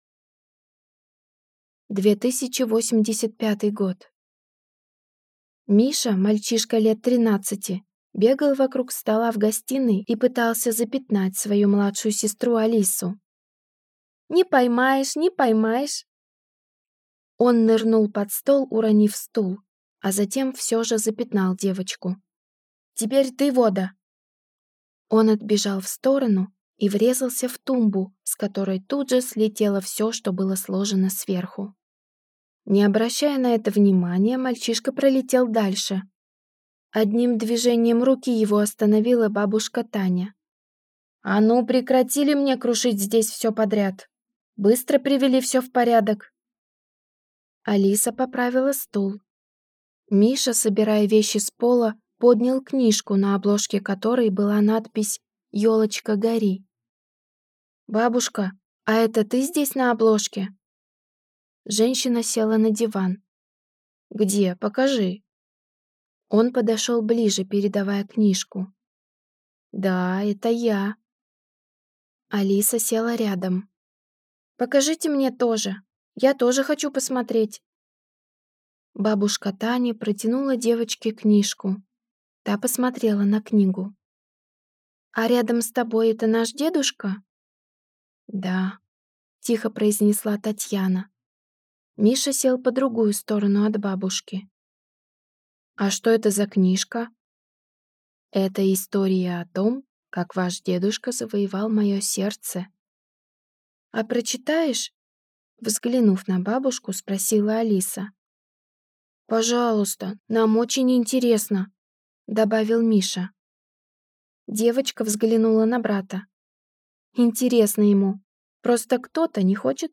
Аудиокнига «Мифы и легенды. Книга 7. Последний из рода Бельских».